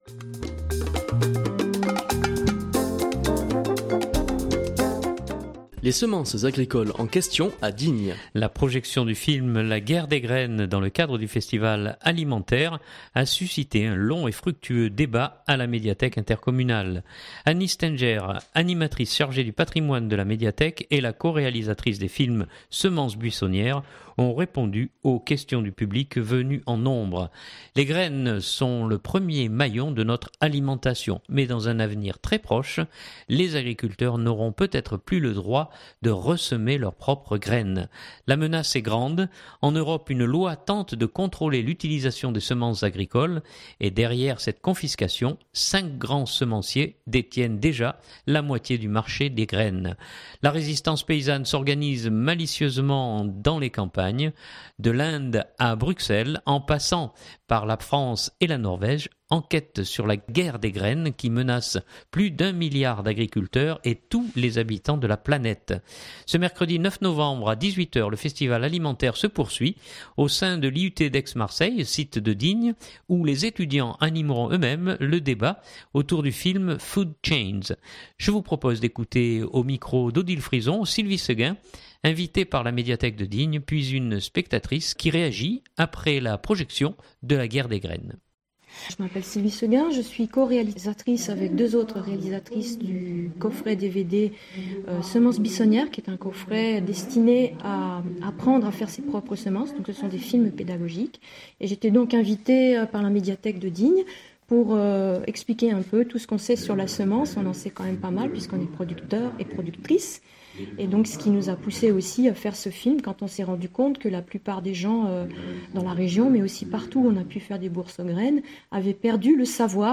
une personne du public